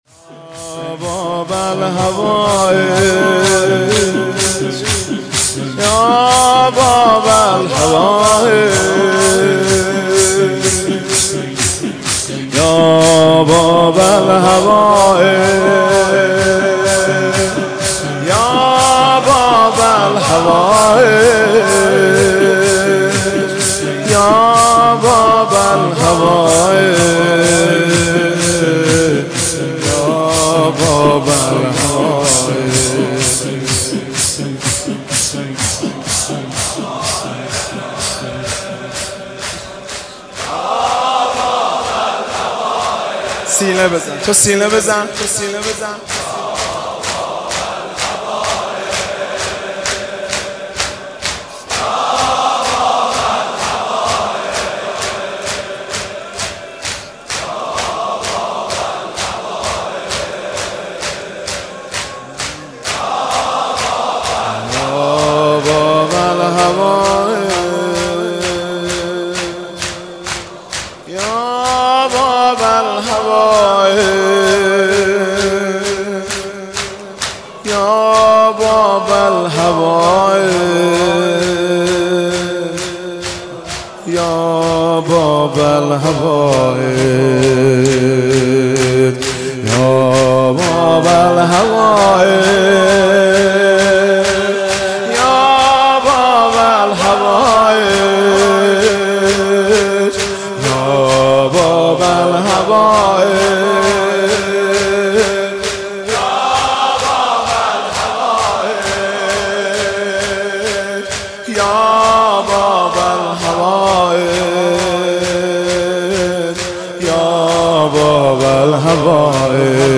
محرم1402 شب نهم -شور - یا باب الحوائج